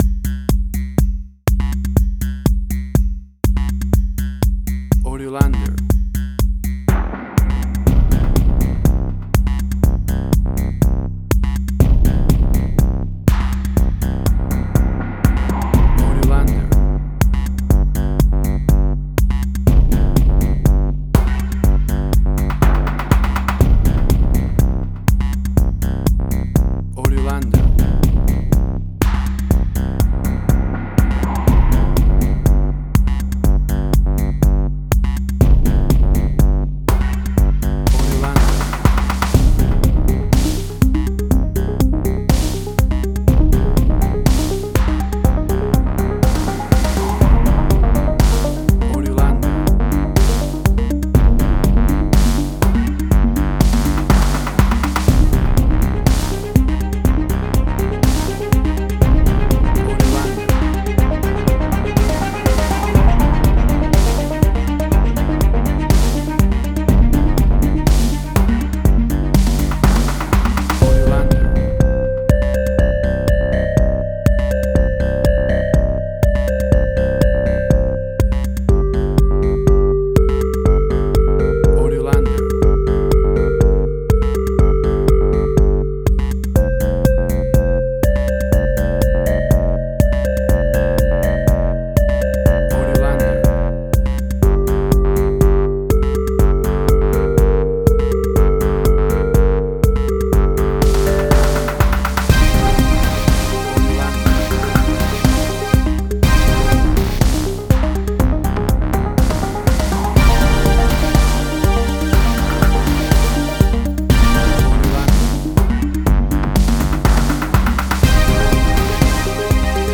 Modern Science Fiction Film, Similar Tron, Legacy Oblivion.
Tempo (BPM): 122